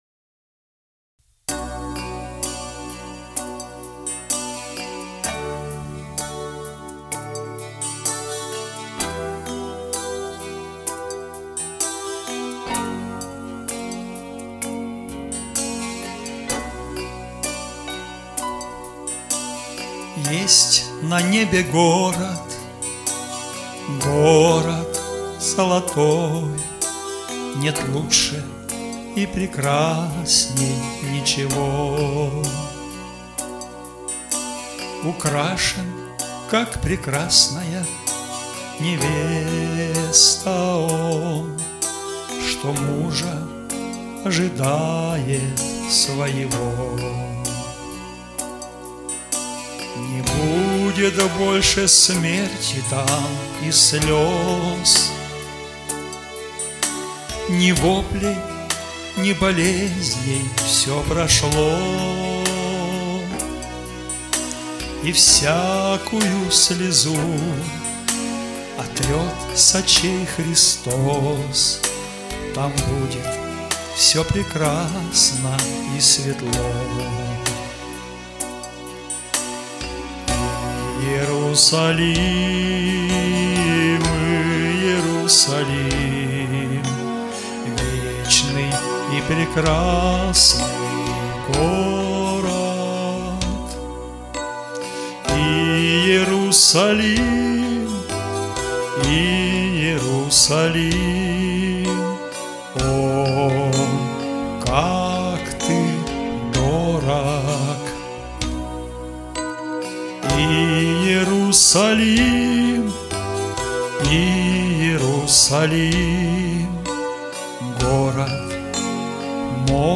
Христианские песни